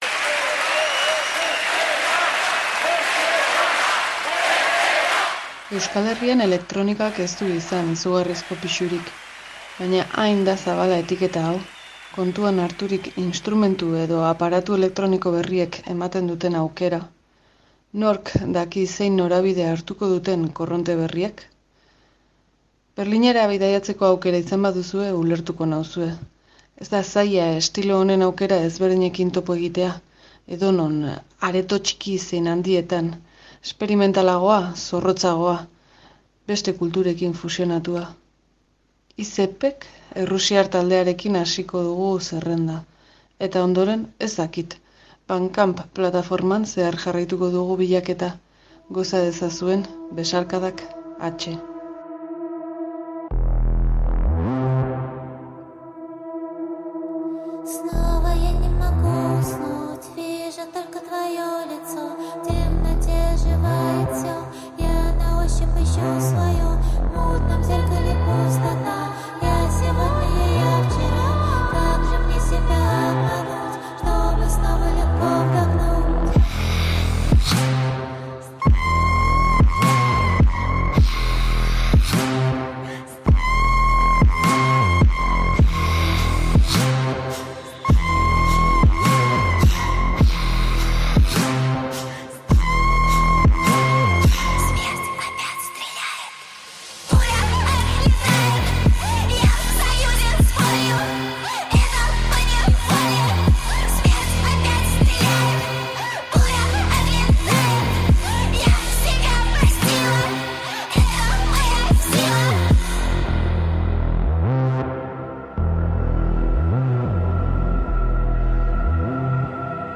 Elektronika, ozeano zabal eta anitz hori.